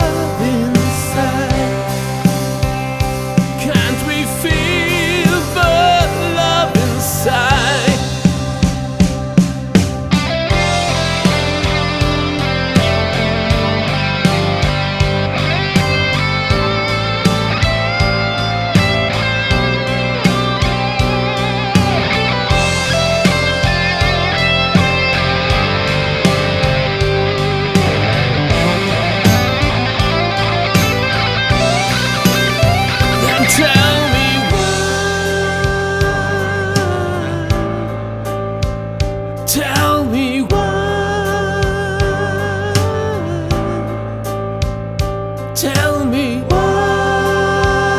WHY -Rock/Pop Ballade-
Eine Alternative dazu wäre es, wenn man direkt nach dem Solo in der Dynamik stark runter geht, statt es weiter auf dem gleichen Level laufen zu lassen.